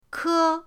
ke1.mp3